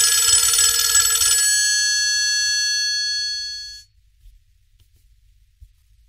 Nokia Ringtones